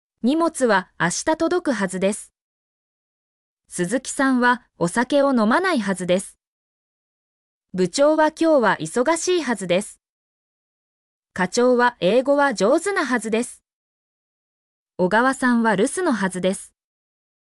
mp3-output-ttsfreedotcom-20_EHMm46do.mp3